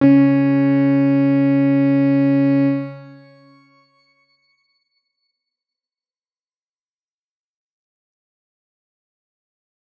X_Grain-C#3-pp.wav